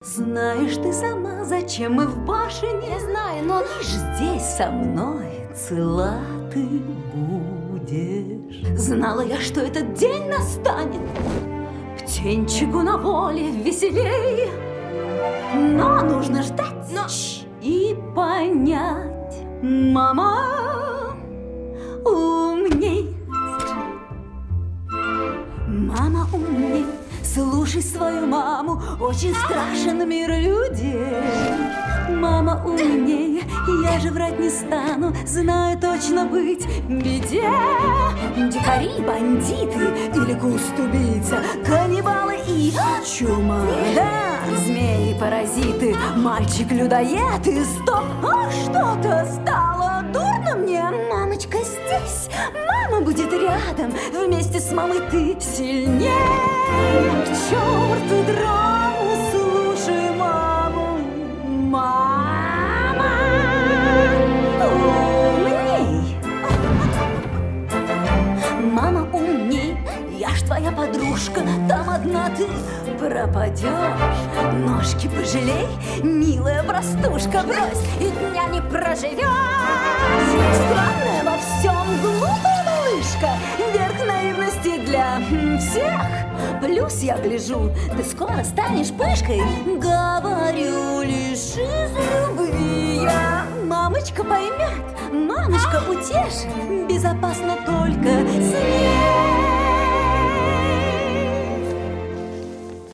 • Качество: Хорошее
• Категория: Детские песни
🎶 Детские песни / Песни из мультфильмов